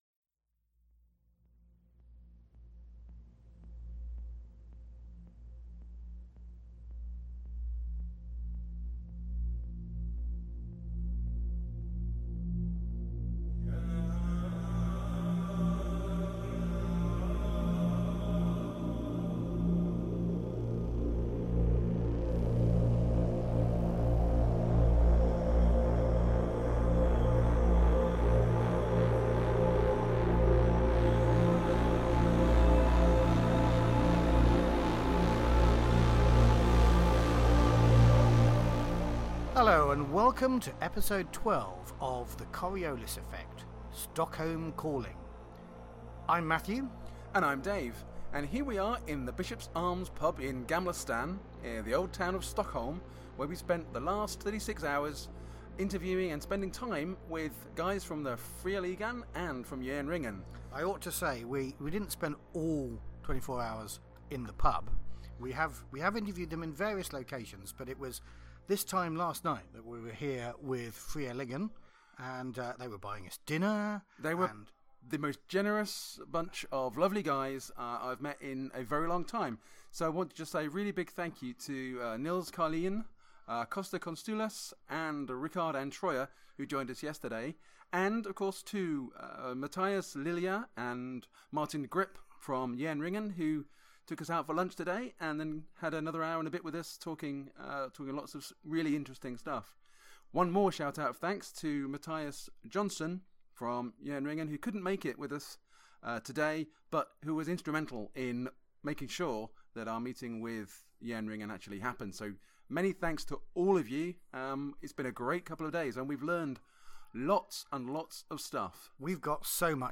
This one entirely recorded in Stockholm. (Some bars were quieter than others, so please forgive the background chatter, especially during the Fria Ligan segment.)